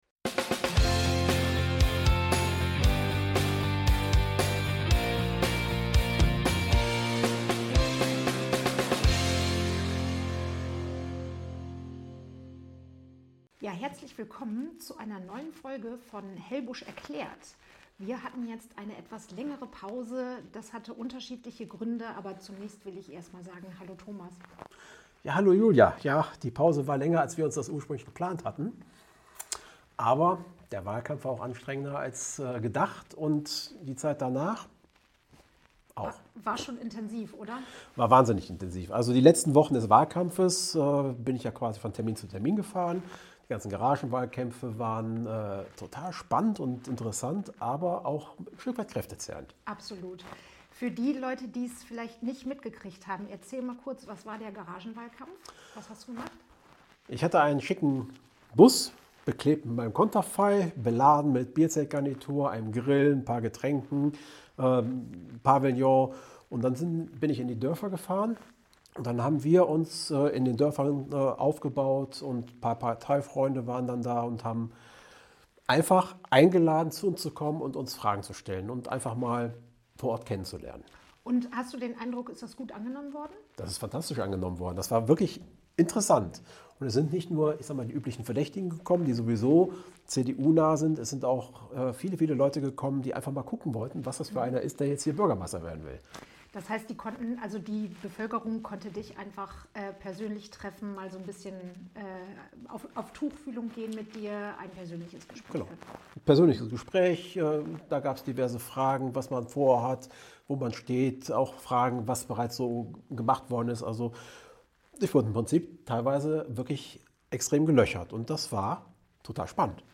Wie soll es mit dem Podcast nach der Wahl weitergehen? Ein kleiner Rückblick auf die Wahl und ein kleiner Ausblick auf die Zukunft. Leider hat es uns die Tonqualität etwas zerschossen - dafür ein dickes "Sorry!"